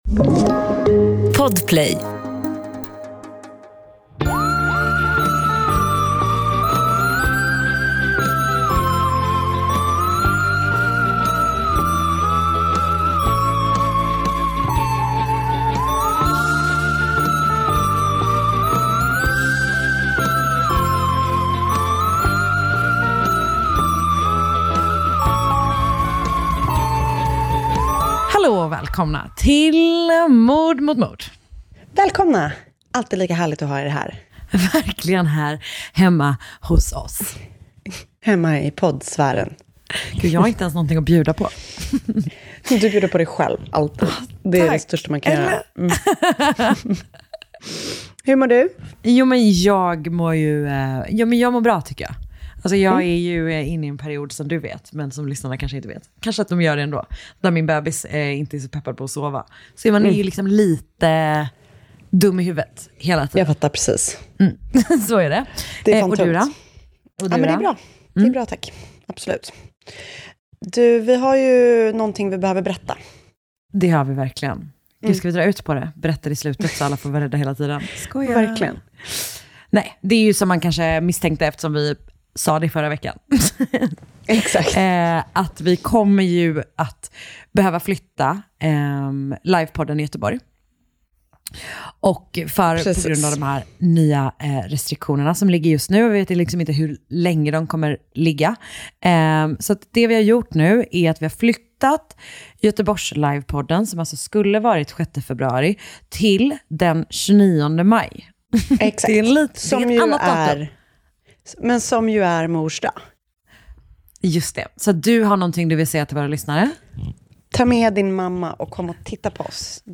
Mord Mot Mord är en vanlig snackig podd, fast om mord. Det är lättsamt prat i ett försök att hantera världens värsta ämne.